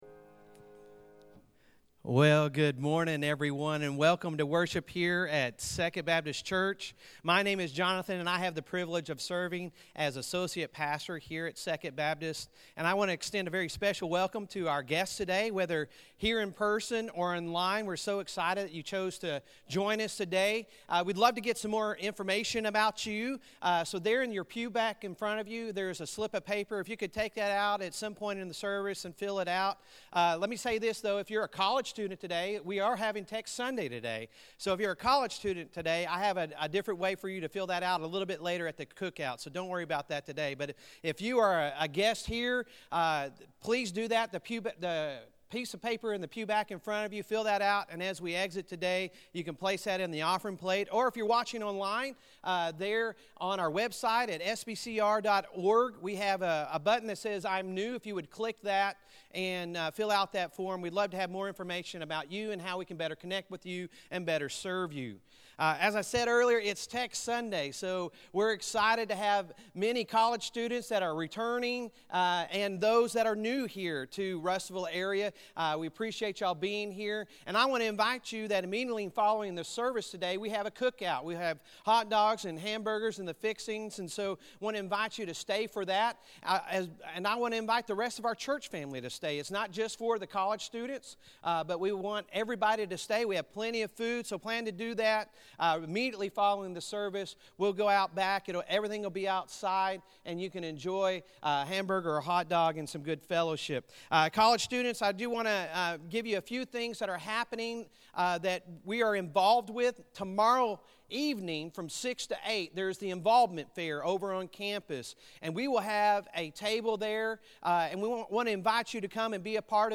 Sunday Sermon August 15, 2021